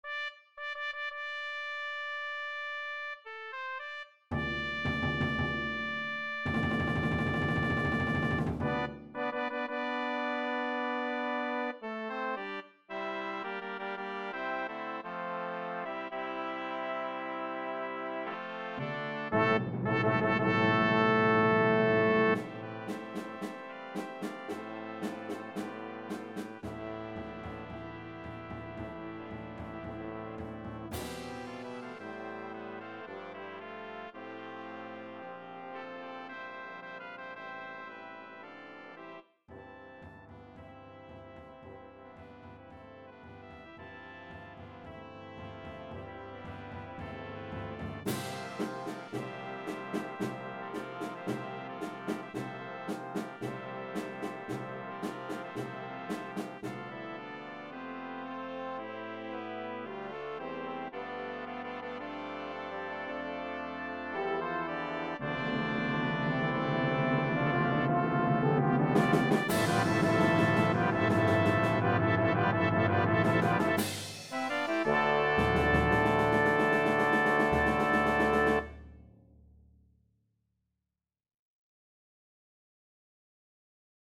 4 Trumpets 1 Horn in F 4 Trombones 1 Tuba 3 Percussion
Timpani, Side Drum and Cymbals Claude